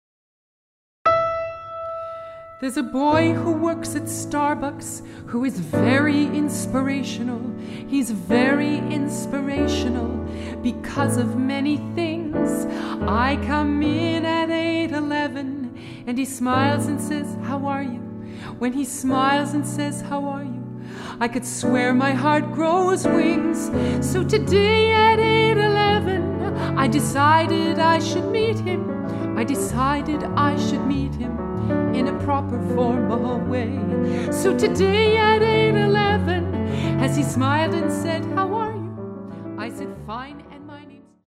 Vocal
Piano
Clarinet
Trumpet Purchase and Download Entire CD